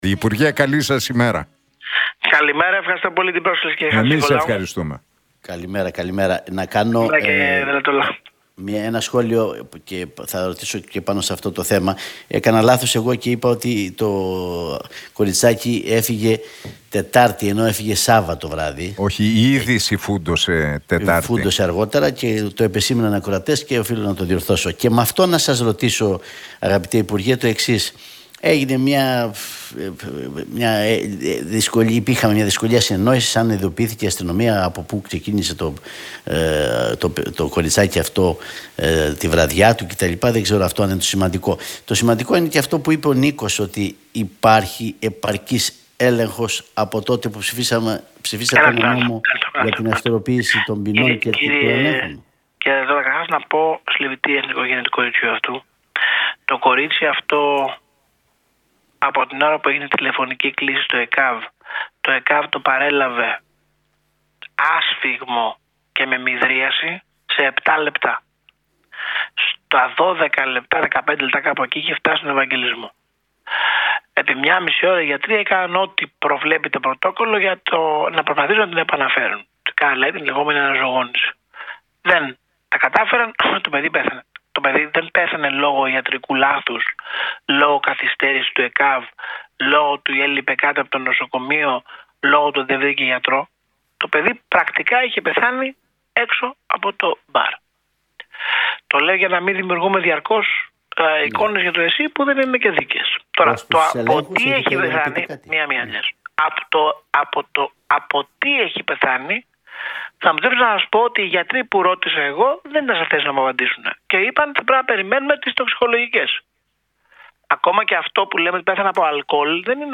κόμμα Τσίπρα μίλησε ο υπουργός Υγείας, Άδωνις Γεωργιάδης στoν Νίκο Χατζηνικολάου και τον Αντώνη Δελλατόλα από την συχνότητα του realfm 97,8.